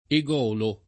[ e g0 lo ]